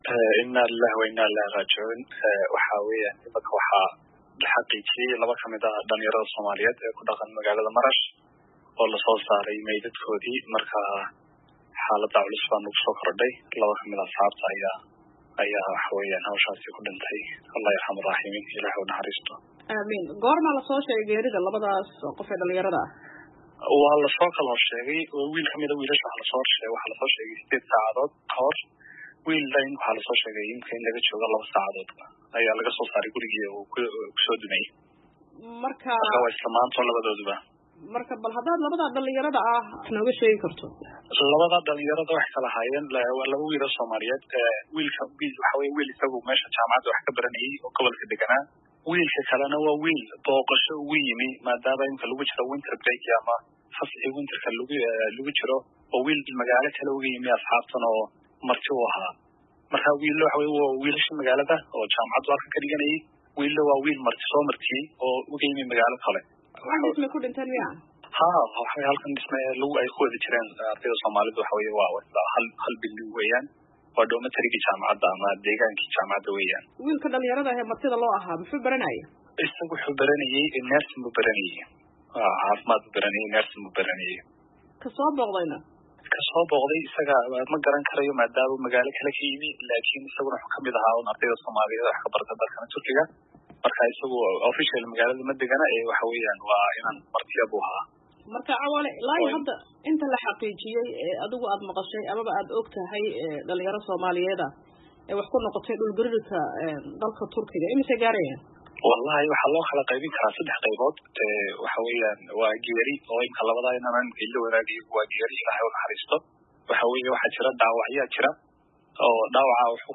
Wareysi: Xaaladda Soomaalida Turkey kadib dhul-gariirkii ku dhuftay